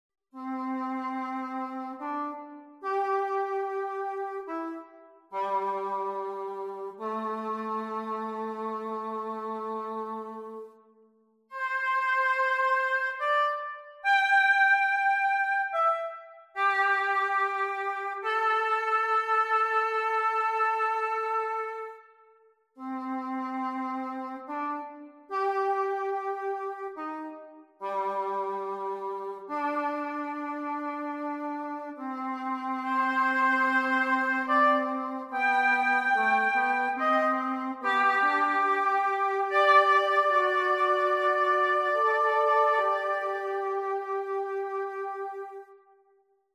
Cor Anglais and Oboe